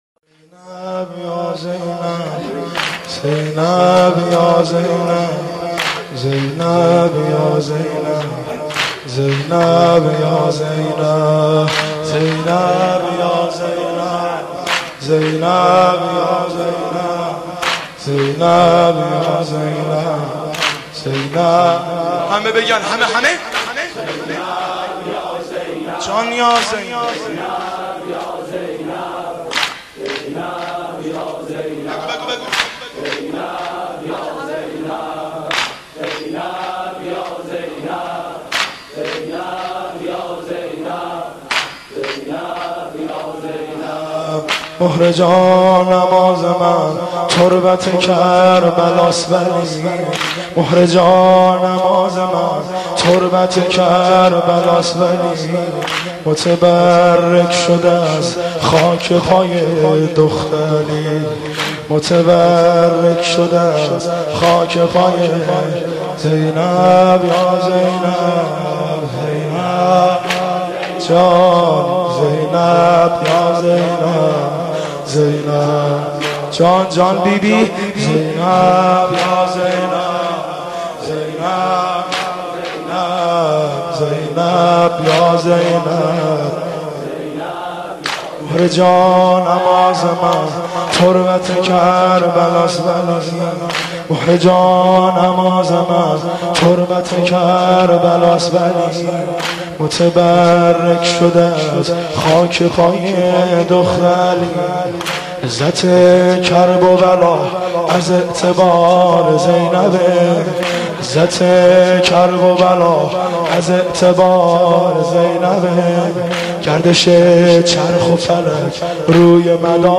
حضرت زهرا خودش آیینه دار زینب است ( واحد )
مخلوق خدا ، خالق ماتم شده زینب ( شعرخوانی )